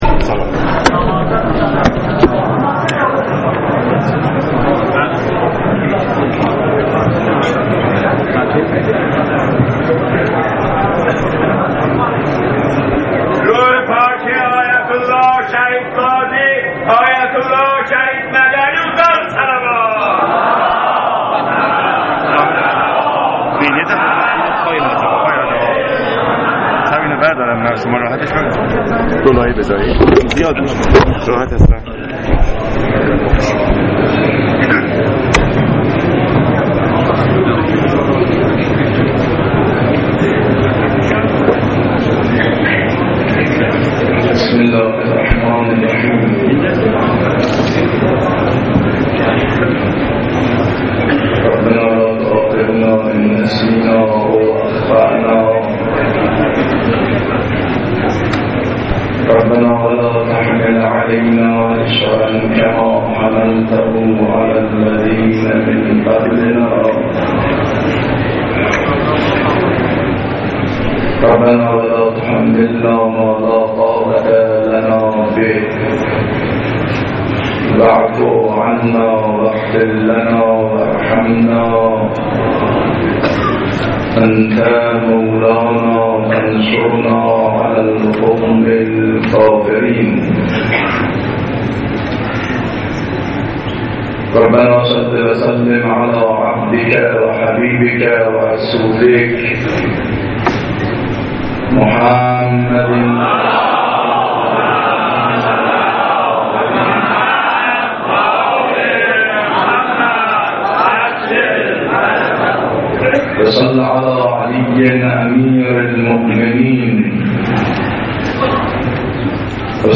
ختم آیت الله مدنی.mp3
ختم-آیت-الله-مدنی.mp3